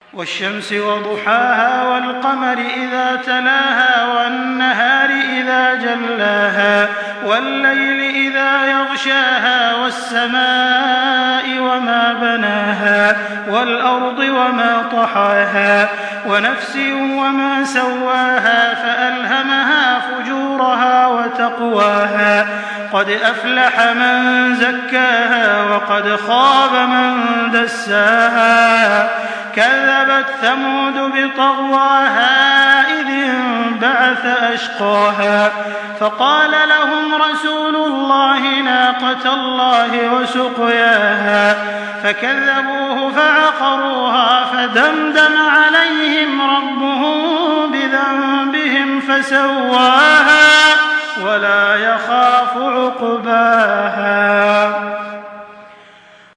Surah Ash-Shams MP3 by Makkah Taraweeh 1424 in Hafs An Asim narration.
Murattal